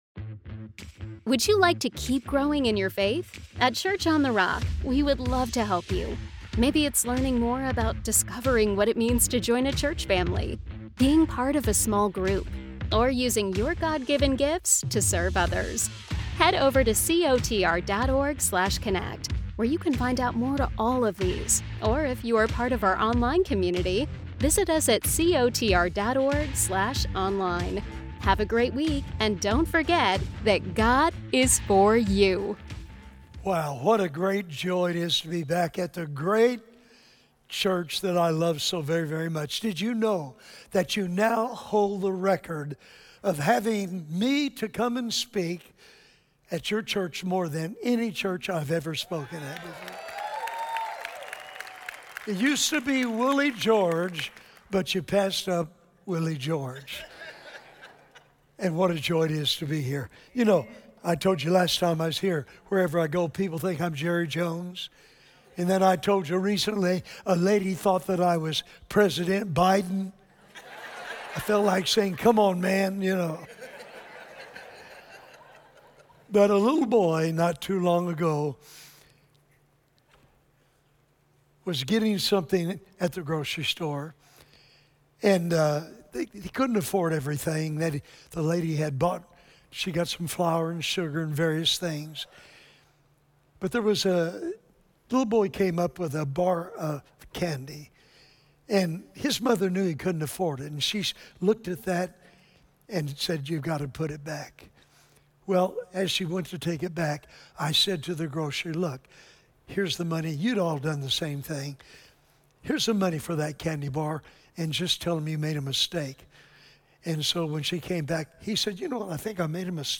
Filled with wisdom, humor, and heartfelt stories from a lifetime in ministry, this message will inspire you to live with purpose, faith, and vision for the generations to come.